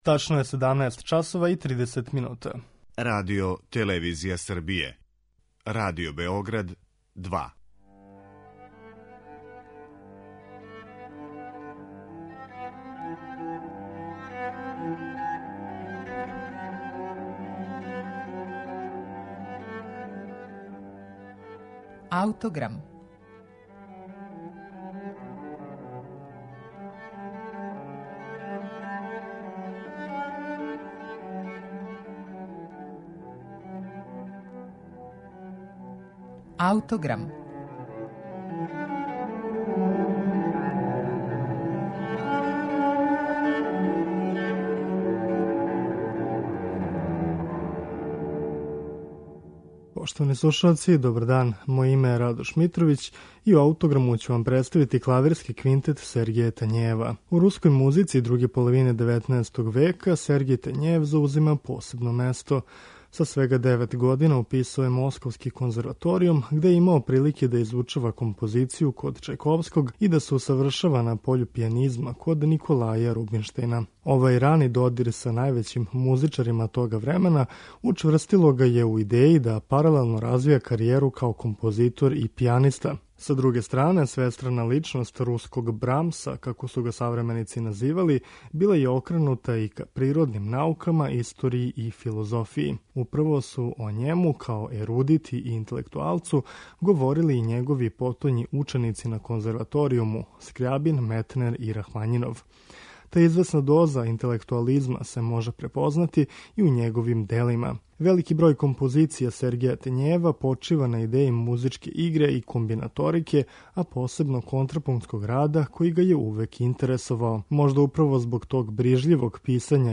Слушаћете Клавирски квинтет Сергеја Тањејева
развијена употреба контрапункта
У данашњем Аутограму, Клавирски квинтет Сергеја Тањејева слушаћете у извођењу пијанисте Михаила Плетњова, виолиниста Вадима Репина и Иље Гринголтса, виолисткиње Нобуко Имаи и виолончелисте Лина Харела.